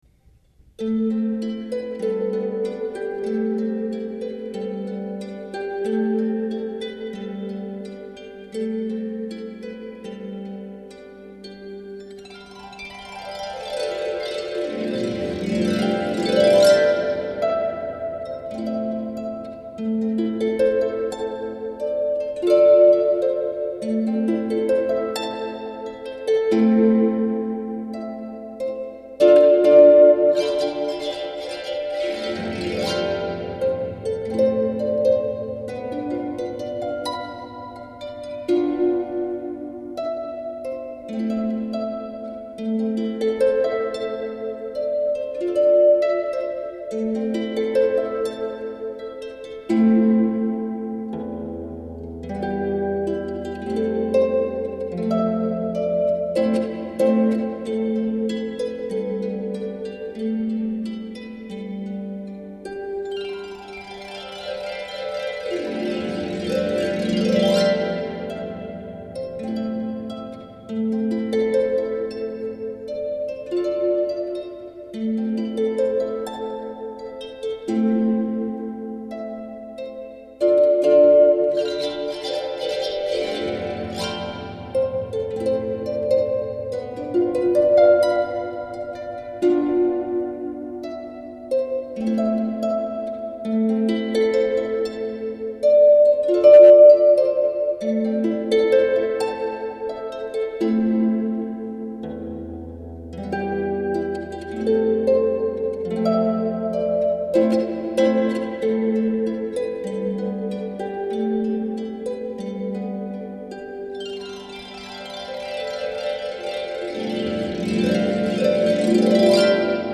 Opera.Aria
soprano